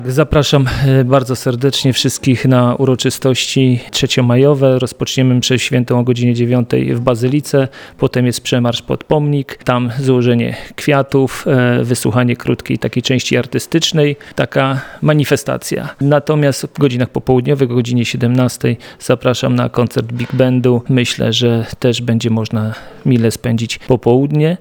Mówi prezydent Mielca Jacek Wiśniewski.